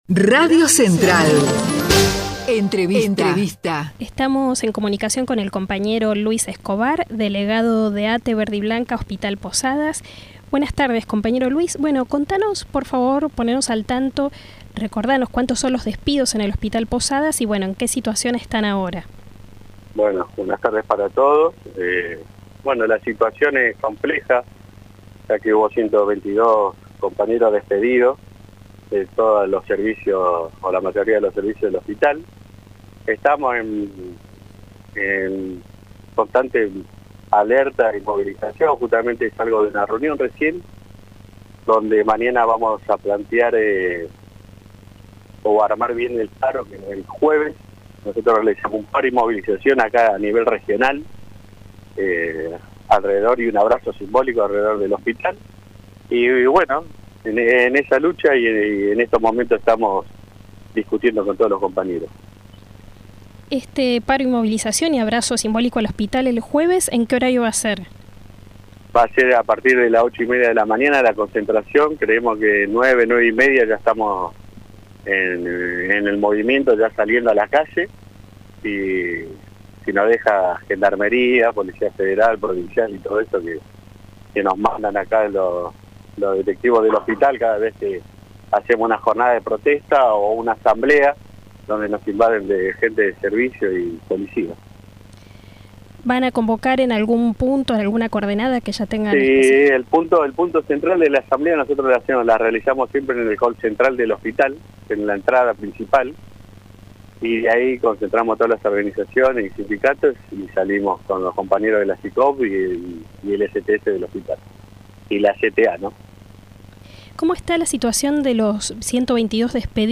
conversó con CTA Comunica sobre la medida de fuerza.